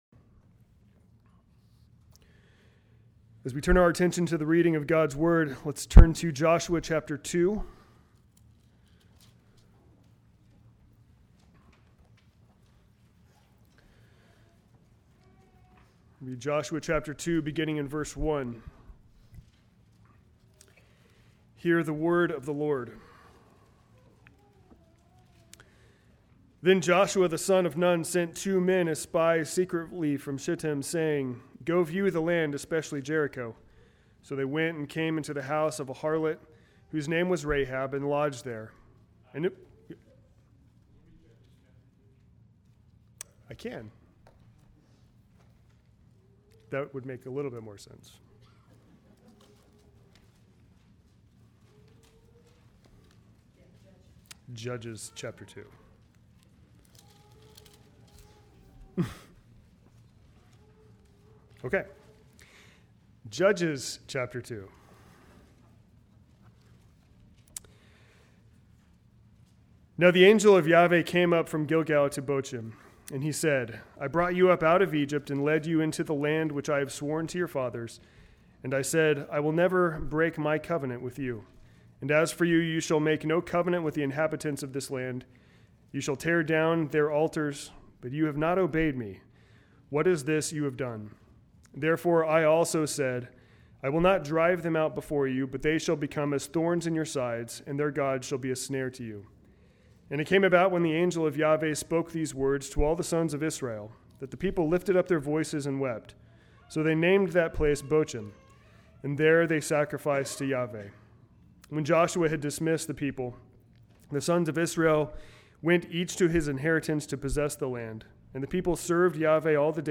Sermons on the Family